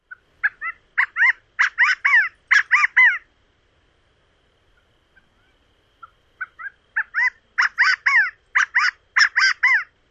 珠颈翎鹑叫声